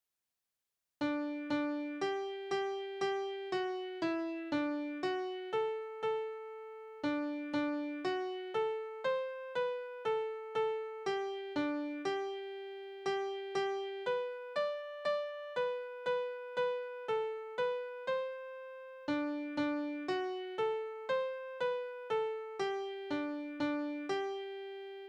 Tonart: G-Dur
Taktart: 3/4
Tonumfang: Oktave
Besetzung: vokal
Anmerkung: Vortragsbezeichnung: Die Viertel ein wenig länger als Achtel zu singen